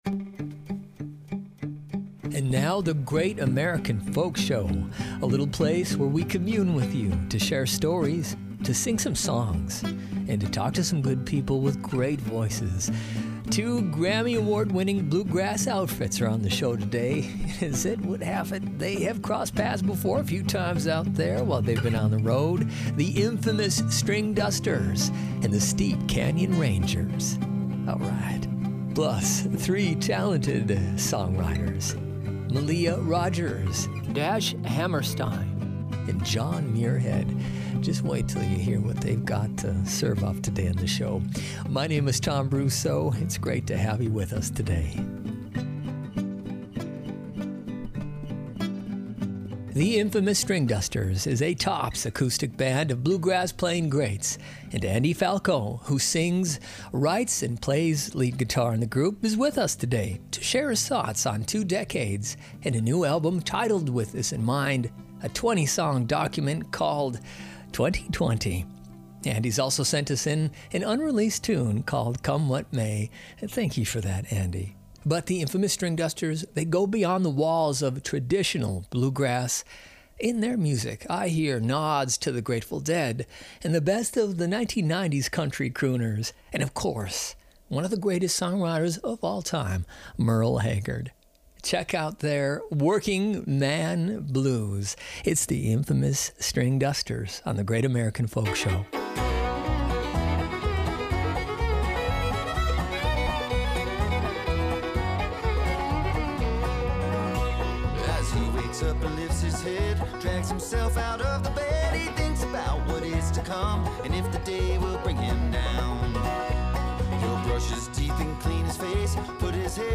The Great American Folk Show Podcast features interviews, music, poetry, and more, curated from the show's beloved radio broadcast.
Be a guest on this podcast Language: en Genres: Arts , Music , Music Interviews Contact email: Get it Feed URL: Get it iTunes ID: Get it Get all podcast data Listen Now...